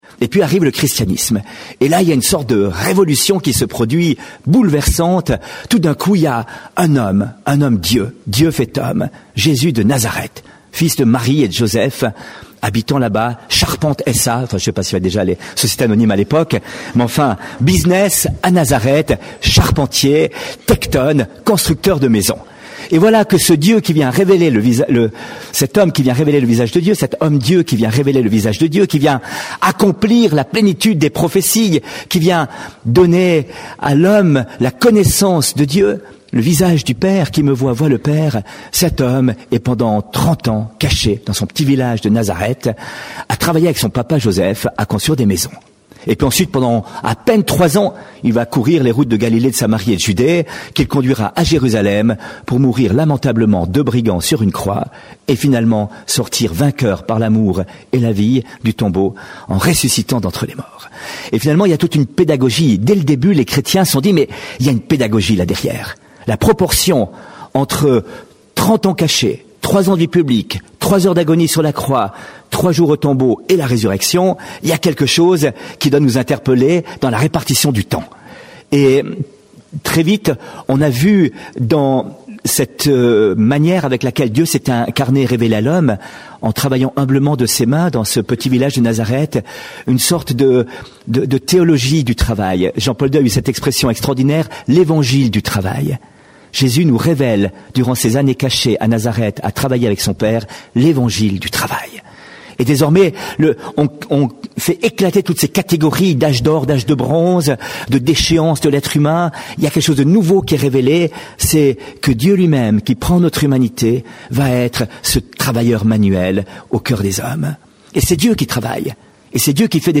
Enregistrement r�alis� dans le cadre du "Forum 2008" de Pr�sence et T�moignage Th�me g�n�ral : Nos relations avec les autres sont essentielles dans nos activit�s. Elles sont parfois complexes. Comment les rendre constructives et �tre "meilleurs ensemble" ?